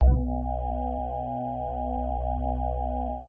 cube_rotate_1.ogg